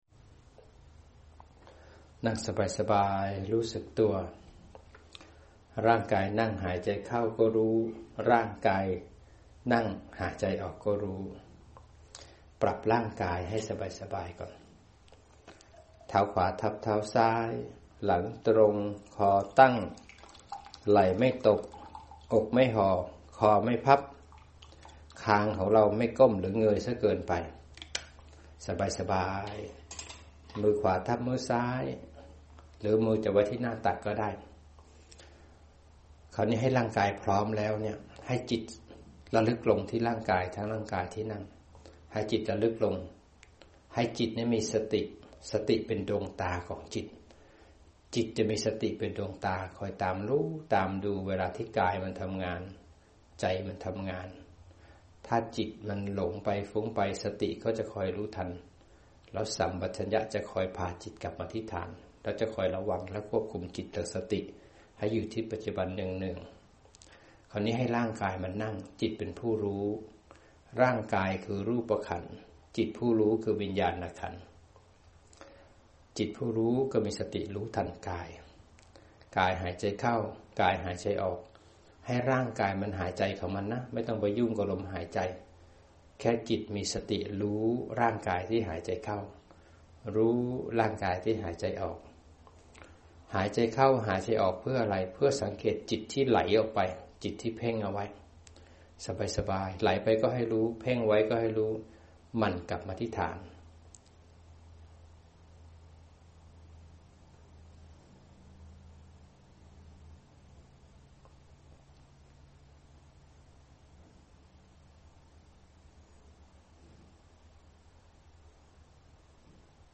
อจ นำจิตฝึกสติปัฏฐานอยู่กับปัจจุุบัน